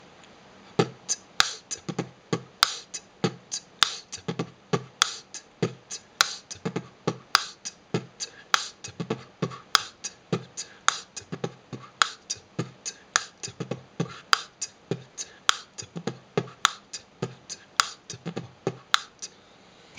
Тест хендклепа
Или мне кажется ил это больше похоже на cl типо цоканье языком??
ну не плохо))) на записи и правда очень щёлкает)) у тебя свой такой хендклап) но над ним ещё можно поработать)